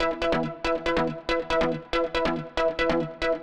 Index of /musicradar/future-rave-samples/140bpm
FR_Minee_140-E.wav